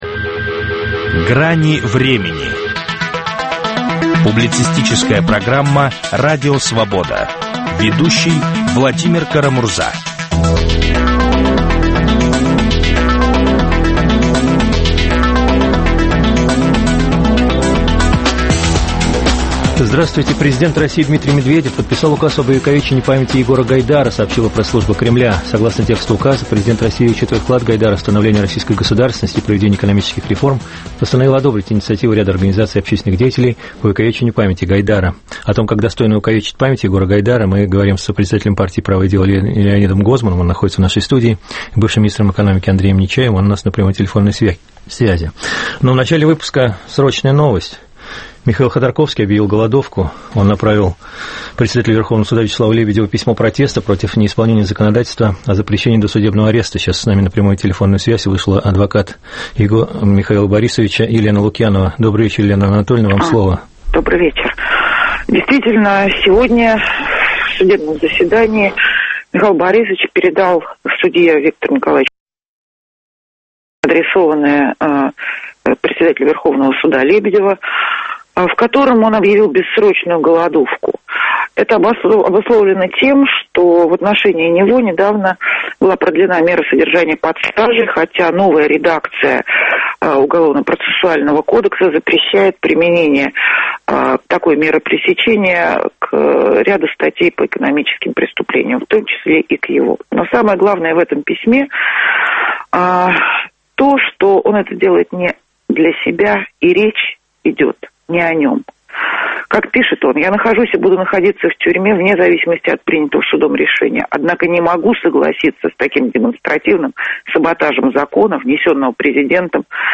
О том, как достойно увековечить память Егора Гайдара, говорим с сопредседателем партии "Правое дело" Леонидом Гозманом и бывшим министром экономики Андреем Нечаевым. Михаил Ходорковский объявил бессрочную голодовку.